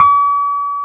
KEY RHODS 12.wav